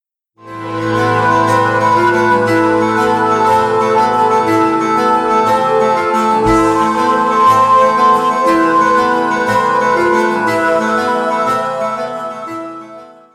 اهنگ زنگ موبایل عاشقانه غمگین بی کلام